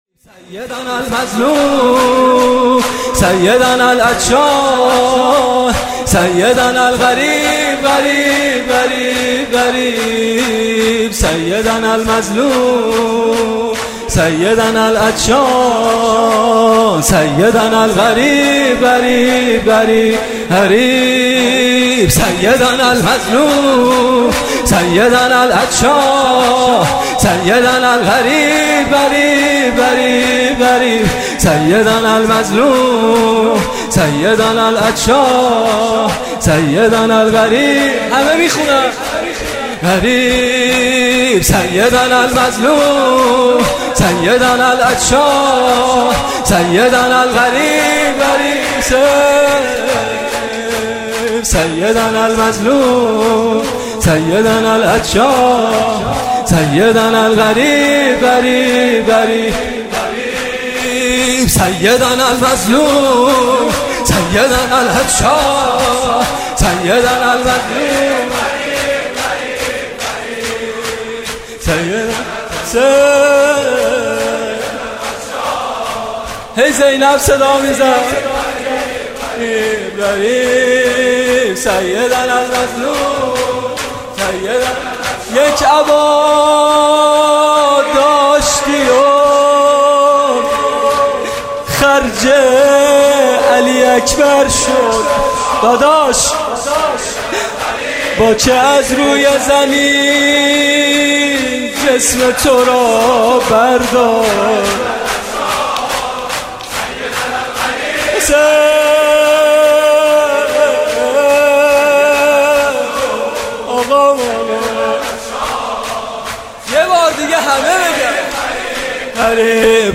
شب چهارم محرم ۱۳۹۸
music-icon شور